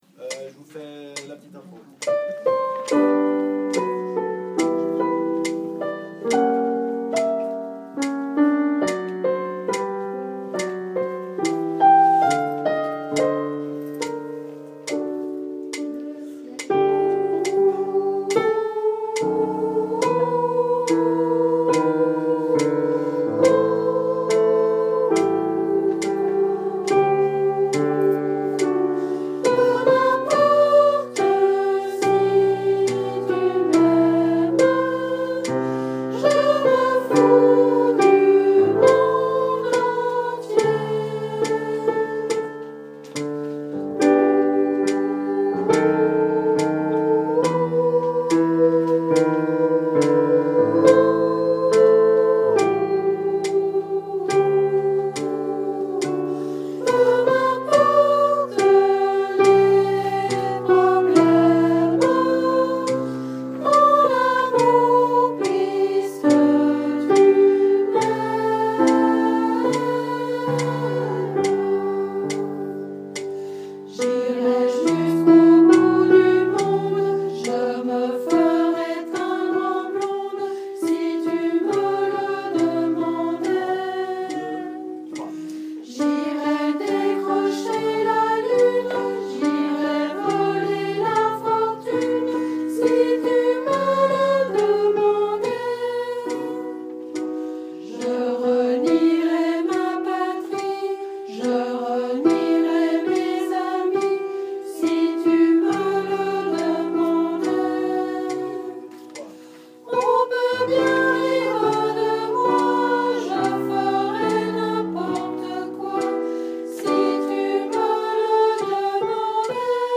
Hymne-A-L-Amour-Sopranis1.mp3